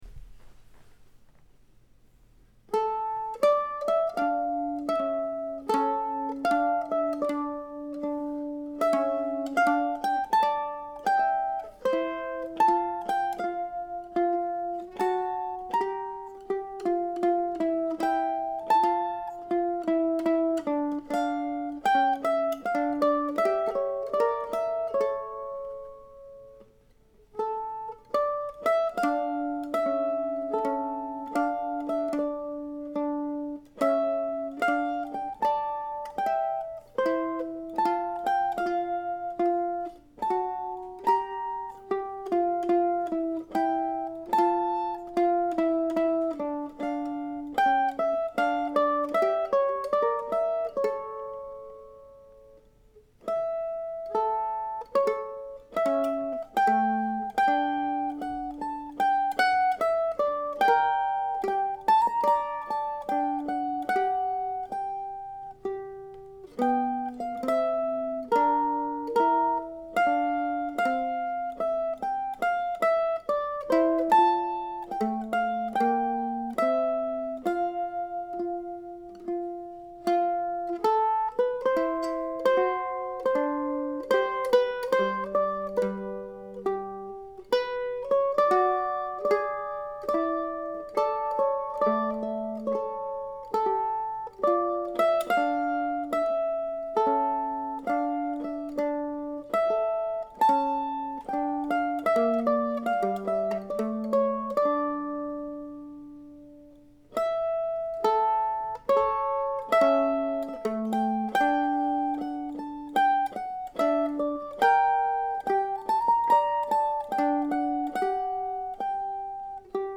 I created a 2nd mandolin part from the original bass line of the piece.
Telemann-ConTenerezzaTWV41D3for2mandolins.mp3